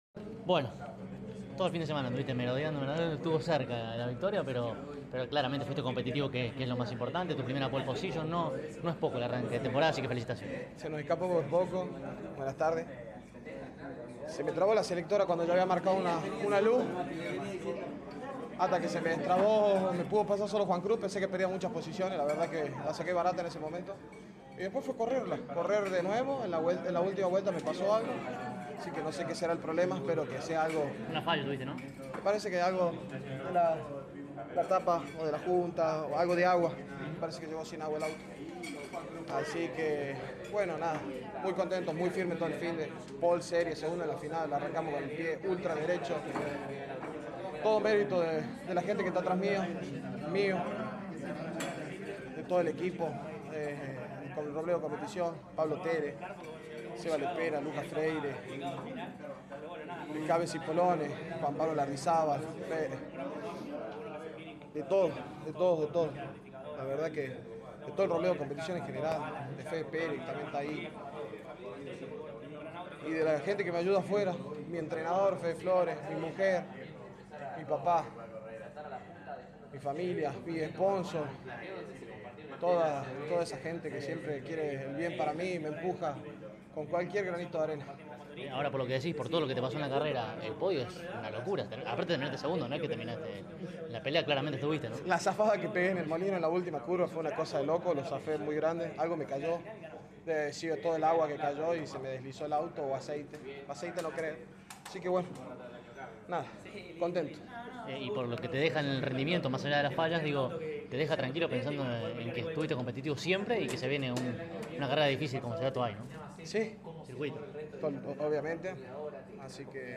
CÓRDOBA COMPETICIÓN estuvo allí presente y dialogó con los protagonistas más importantes al cabo de cada una de las finales.